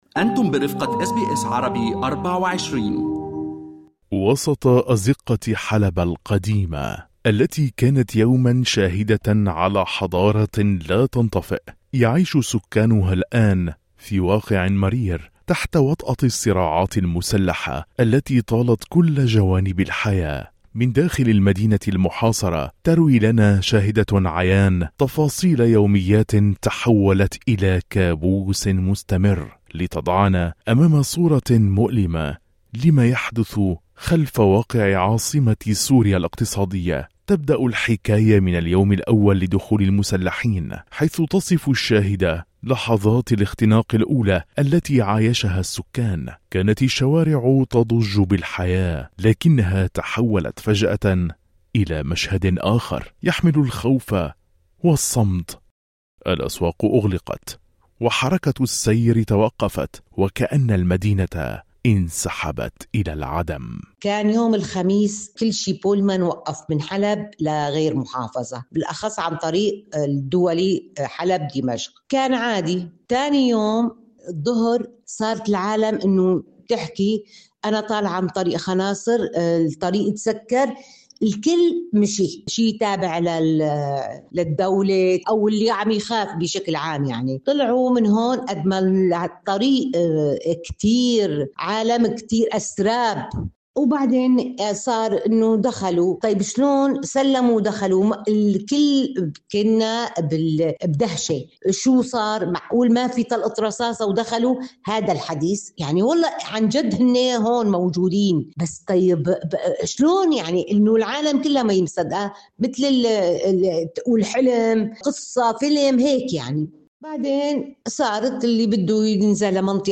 من داخل مدينة حلب، التي كانت يوماً منارةً حضارية، تروي لنا شاهدة عيان تفاصيل الحياة تحت وطأة الصراعات المسلحة المستمرة.